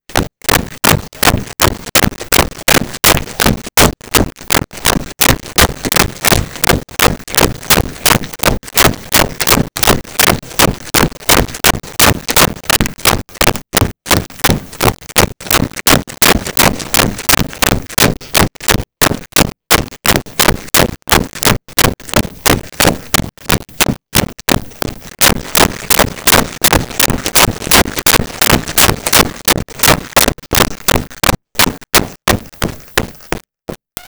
Footsteps Run Hard Floor 01
Footsteps Run Hard Floor 01.wav